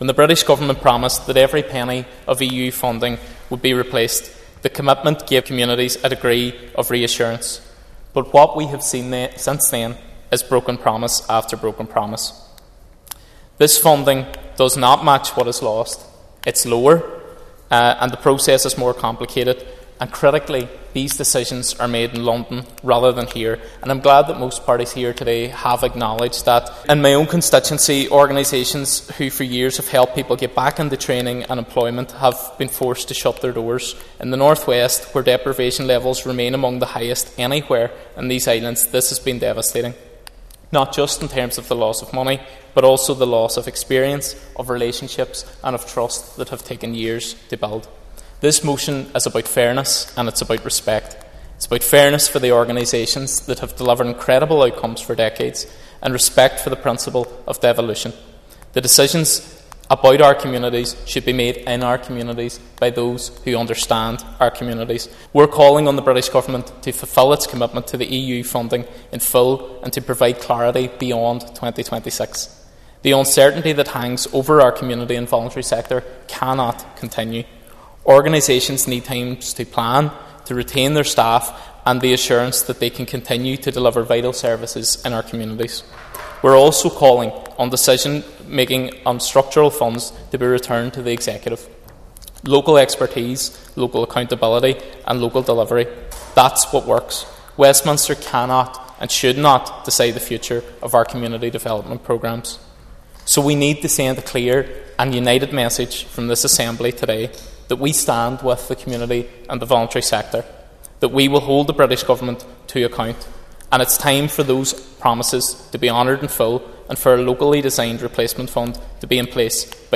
Closing the debate, Foyle MLA Padraig Delargy said Brexit resulted in the loss of a vast amount of community funding and, despite previous promises, the UK government has not replaced this money……………